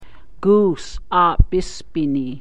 Goose
98624-goose.mp3